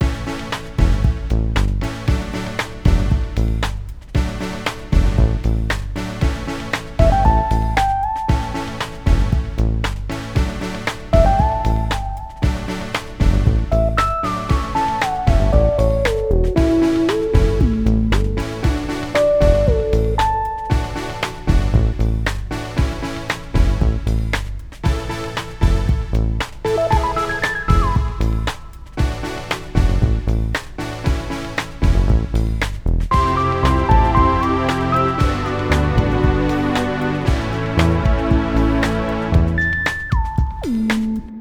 Pop (bucle)
pop
melodía
repetitivo
rítmico
sintetizador
Sonidos: Música